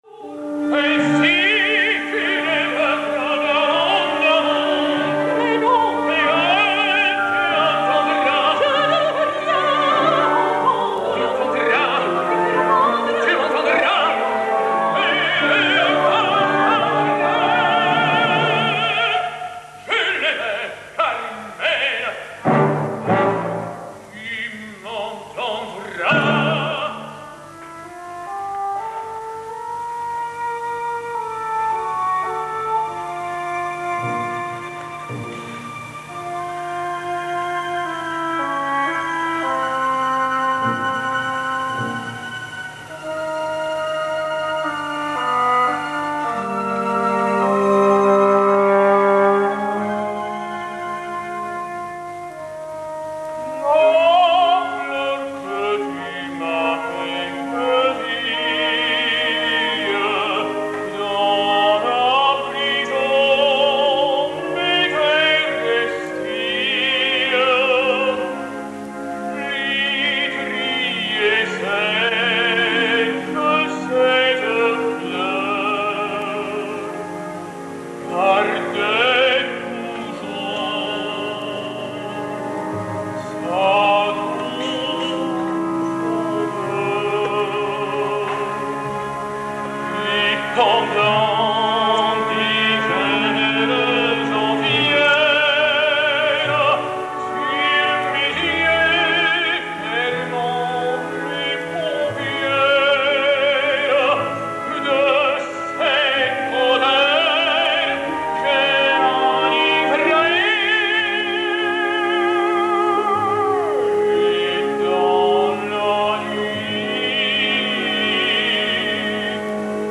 (ária de D. José)
ariaDJose.mp3